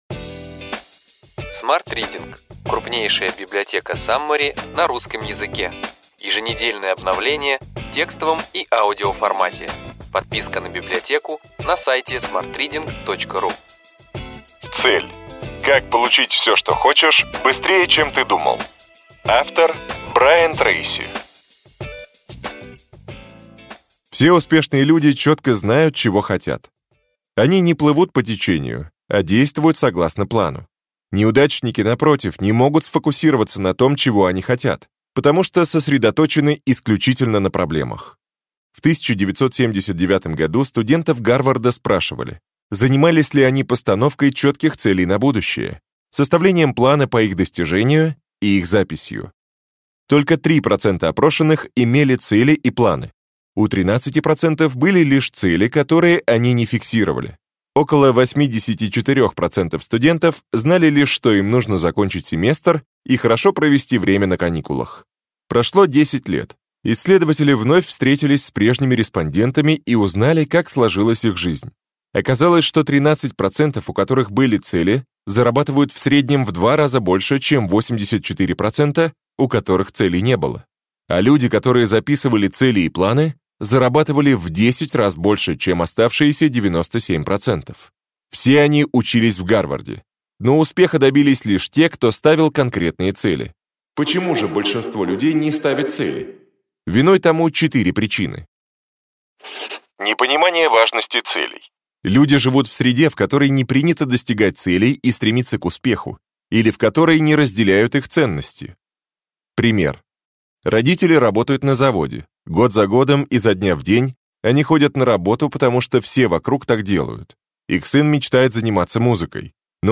Аудиокнига Ключевые идеи книги: Цель! Как получить все, что хочешь, быстрее, чем ты думал.